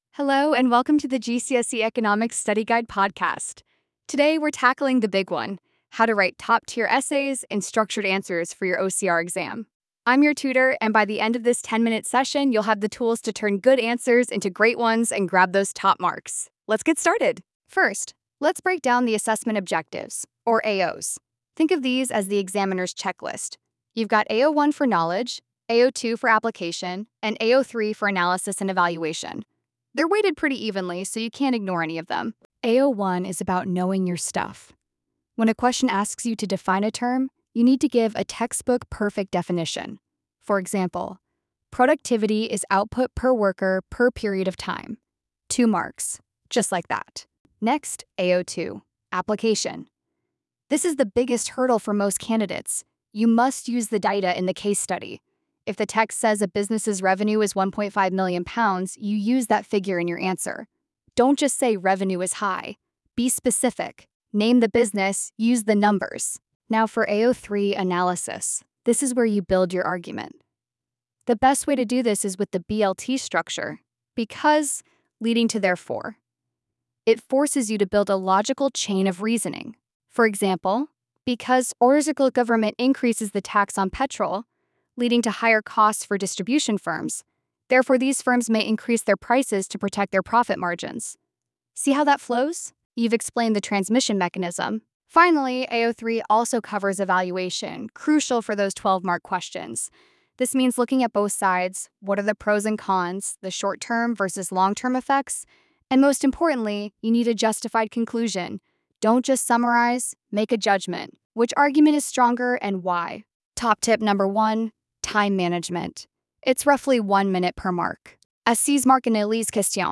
Listen to our expert tutor break down essay technique.